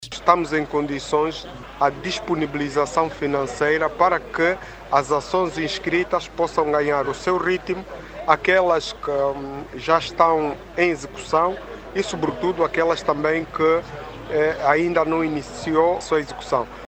Manuel Gonçalves, falava a margem de uma visita realizada ao município de Cacuaco.